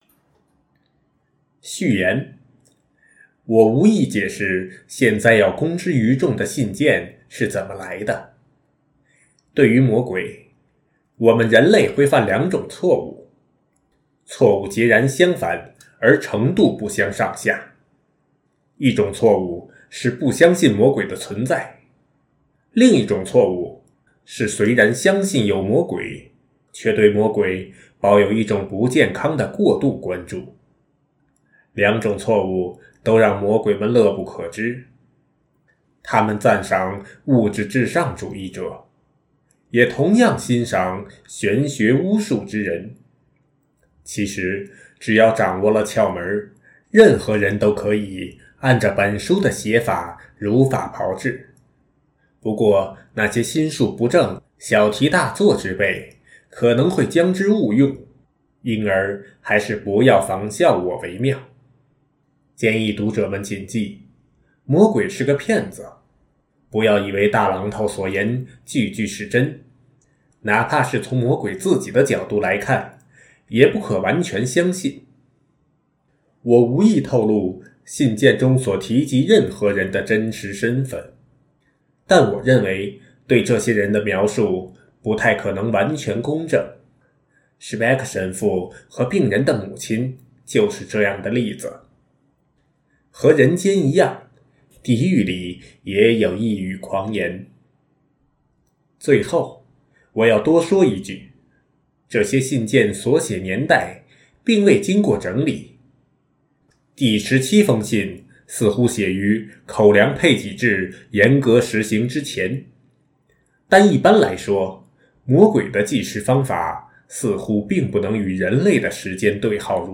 首页 > 有声书 | 灵性生活 | 魔鬼家书 > 魔鬼家书：序言